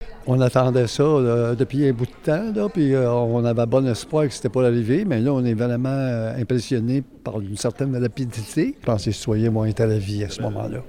Pour le conseiller municipal, Georges Painchaud, c’est une très bonne nouvelle pour son district:
Une conférence de presse était organisée à la salle de la mairie par le ministère des Affaires municipales et de l’Habitation pour annoncer les subventions.